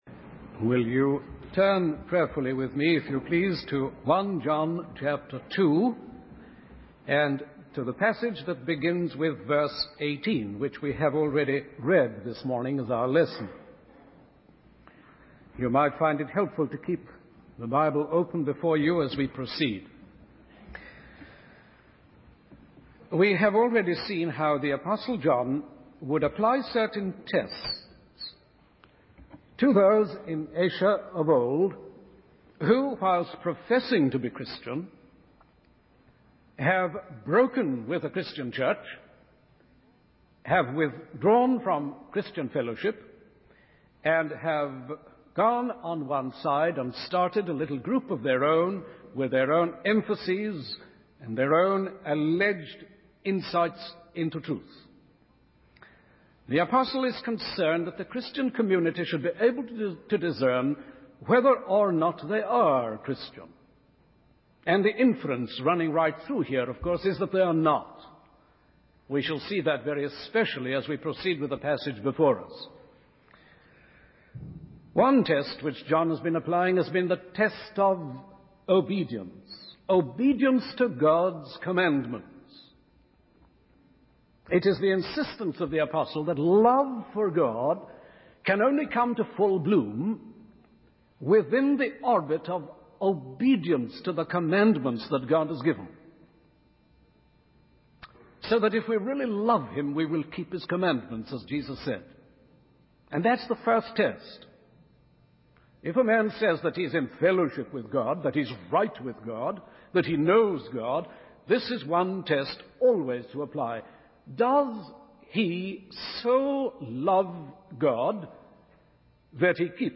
In this sermon, the speaker begins by asking the audience to imagine themselves outside of our world, free from the constraints of time and space. He then introduces the concept of the last hour, as proclaimed by the apostle John in the Bible.